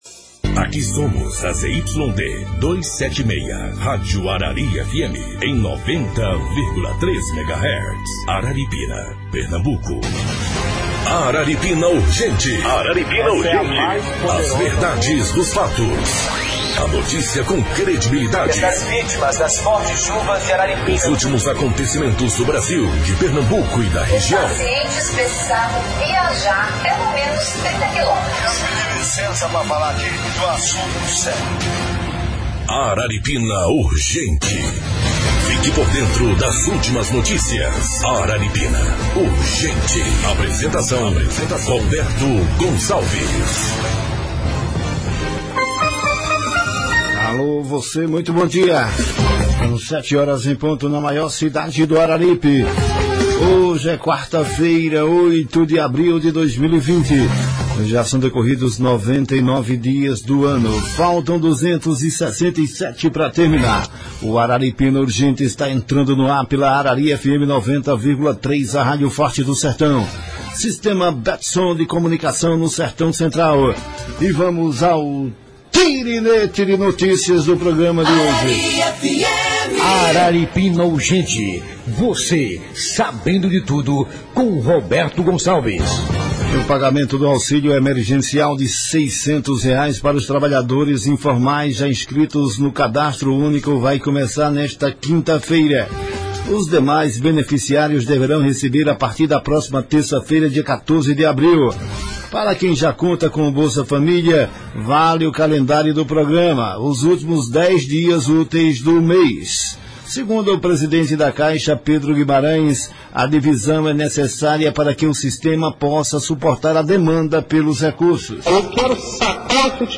Arari FM 90,3